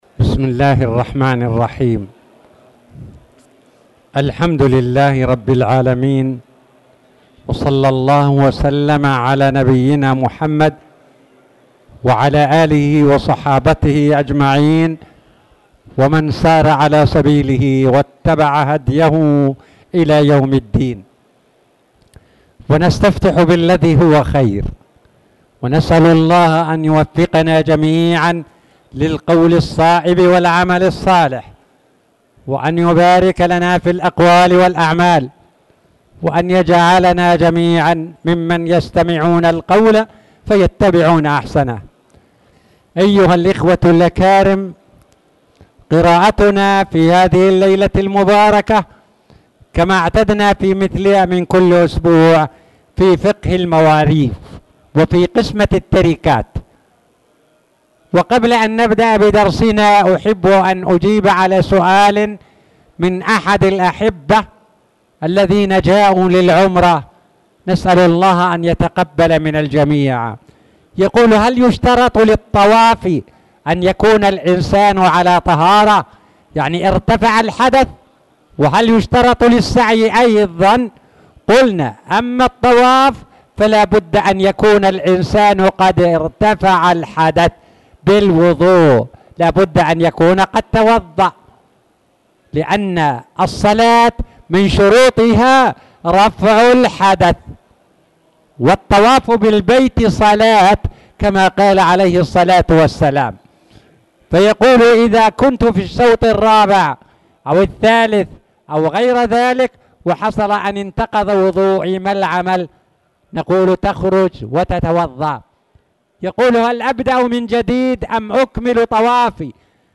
تاريخ النشر ٣٠ محرم ١٤٣٨ هـ المكان: المسجد الحرام الشيخ